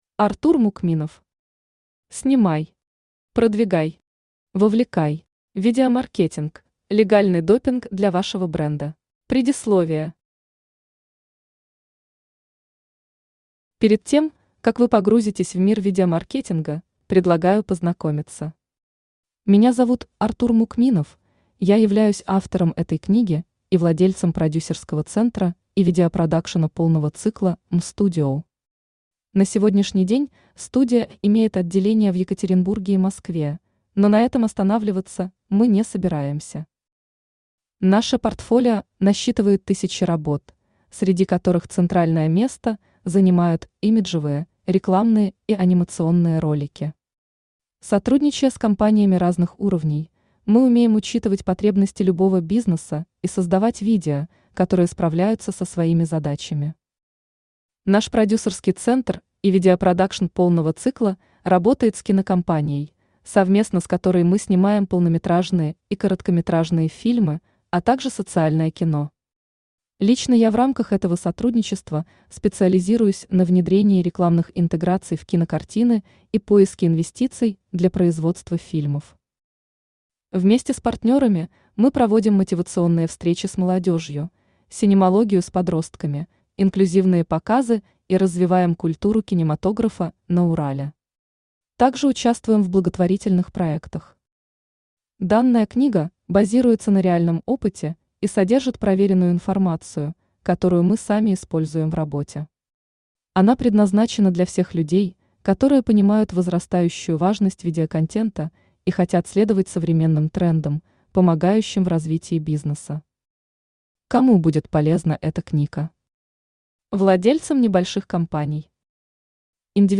Аудиокнига СНИМАЙ. ПРОДВИГАЙ. ВОВЛЕКАЙ. Видеомаркетинг – легальный допинг для вашего бренда | Библиотека аудиокниг
Видеомаркетинг – легальный допинг для вашего бренда Автор Артур Мукминов Читает аудиокнигу Авточтец ЛитРес.